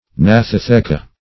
Search Result for " gnathotheca" : The Collaborative International Dictionary of English v.0.48: Gnathotheca \Gnath`o*the"ca\, n.; pl.